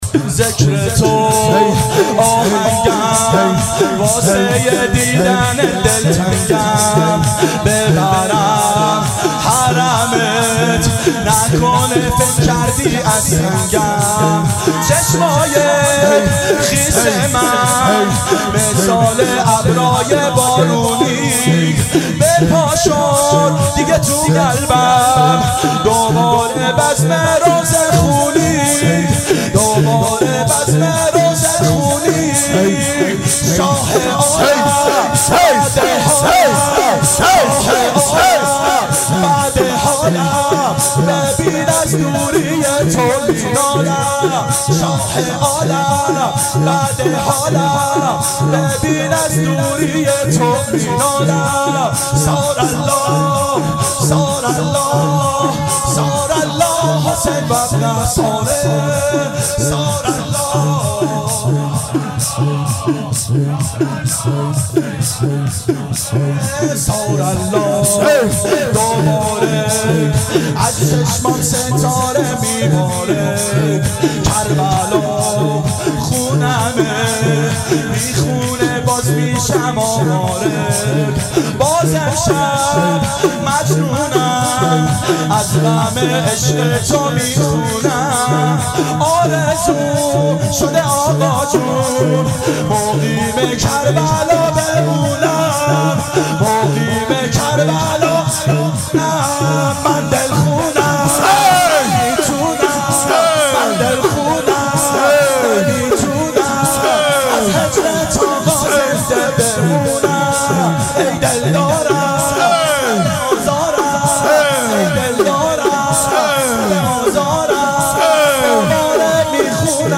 • دهه اول صفر سال 1390 هیئت شیفتگان حضرت رقیه س شب دوم (شب شهادت)